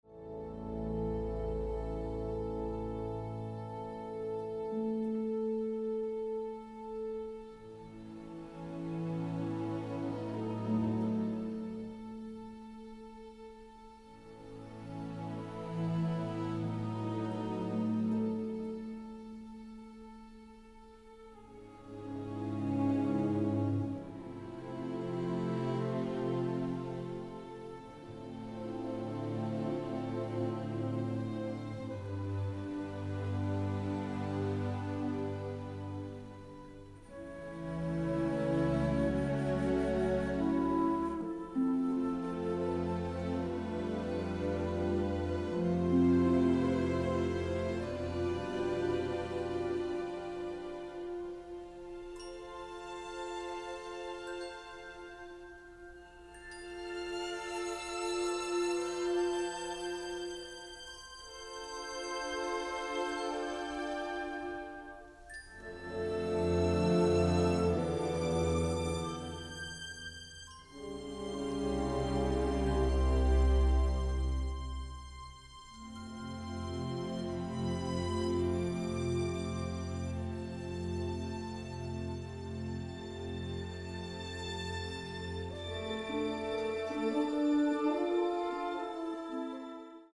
orchestral